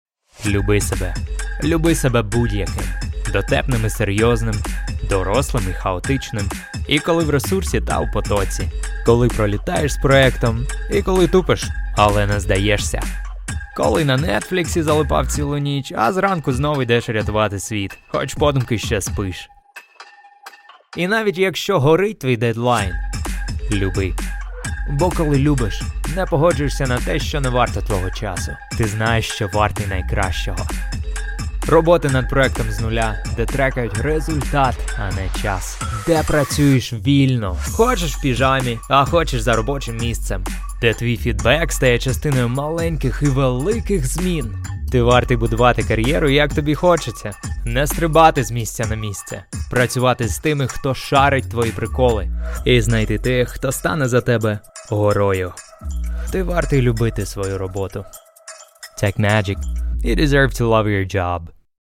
Funkspot des Bundesministeriums für Gesundheit